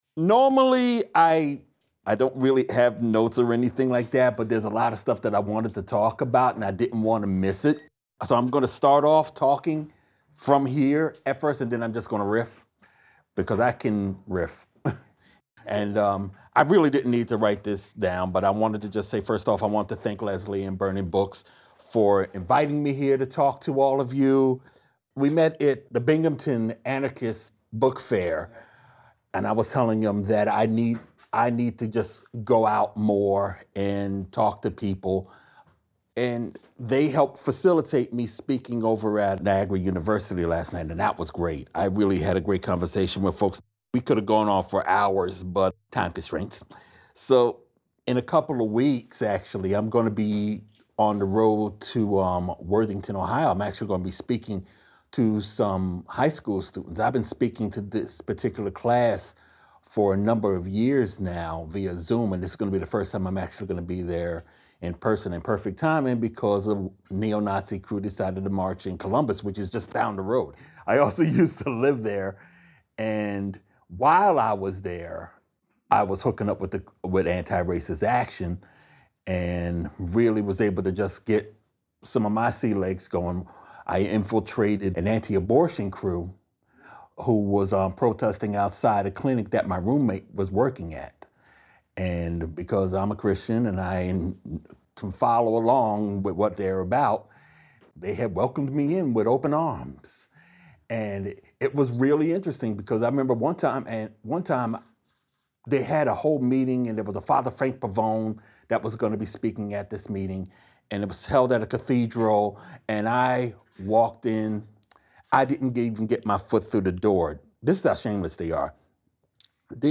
Location Recorded: Burning Books, Buffalo, NY